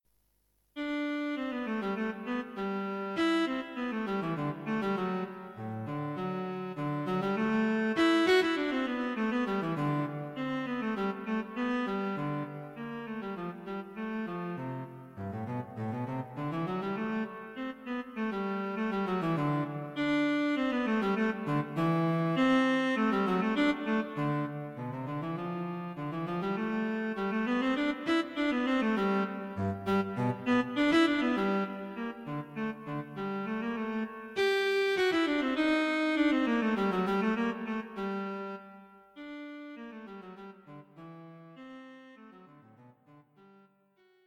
A set of five new pieces for solo cello.